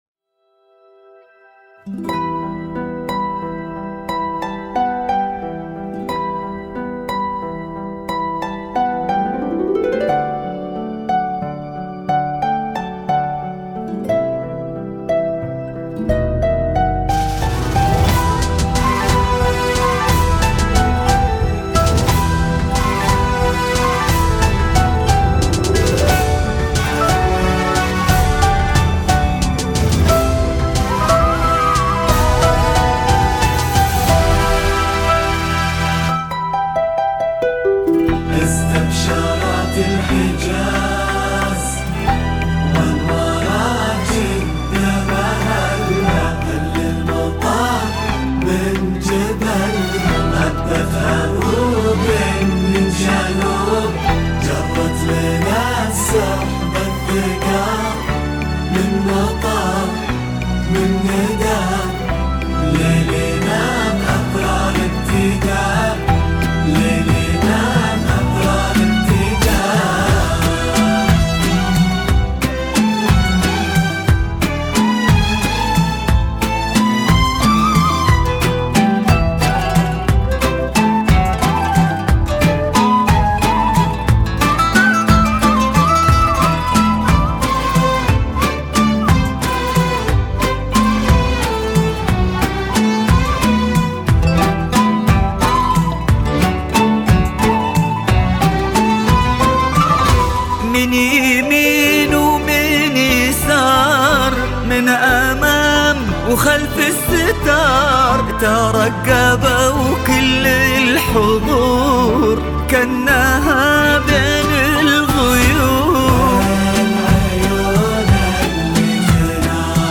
بذكاء الاصطناعي
موسيقي وبدون موسيقي